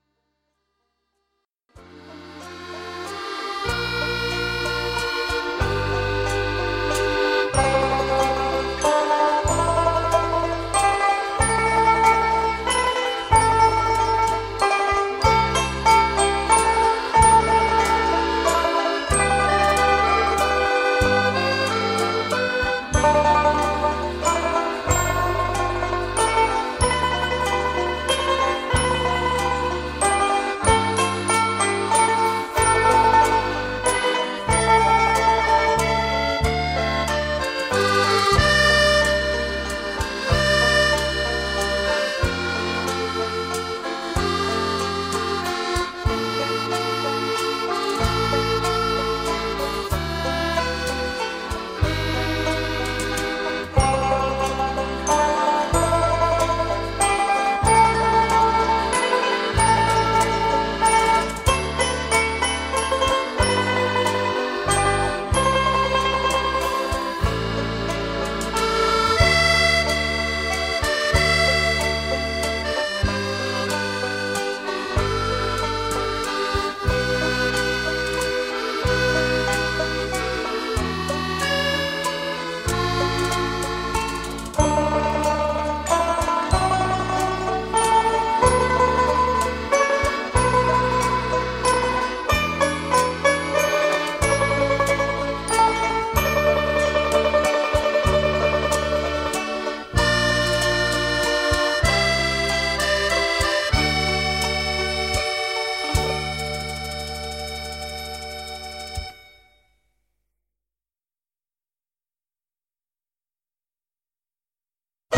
This song is in 3/4 waltz time.